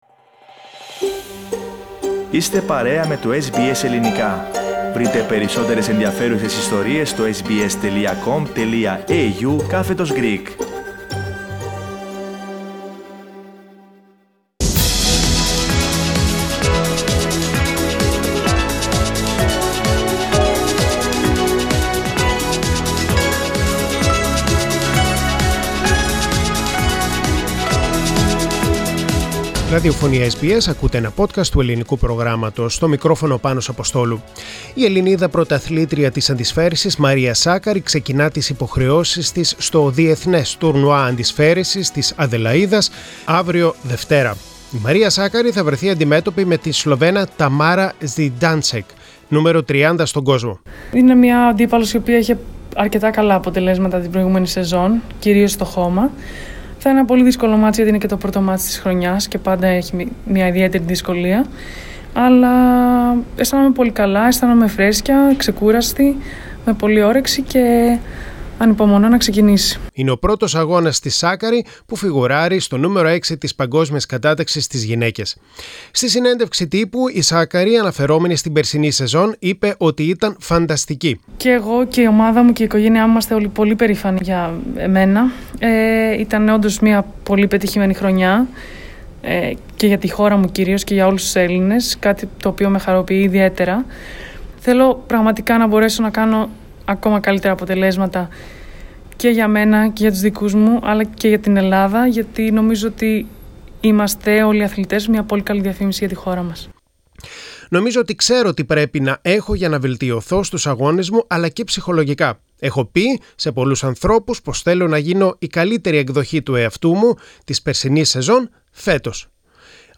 Πατήστε Play στην κεντρική φωτογραφία για να ακούσετε το Αθλητικό Δελτίο ΔΕΙΤΕ ΑΚΟΜΗ Μία νίκη, δύο ήττες για την Ελληνική ομάδα στο ATP Cup Share